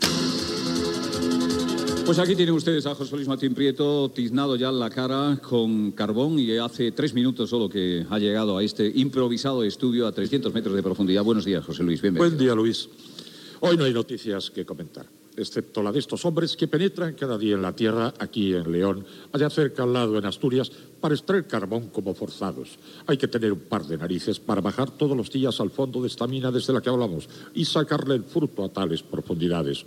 Programa emès des del fons de la mina de Villablino (LLeó).
Info-entreteniment